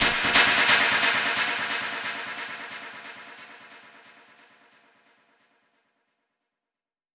Index of /musicradar/dub-percussion-samples/134bpm
DPFX_PercHit_C_134-05.wav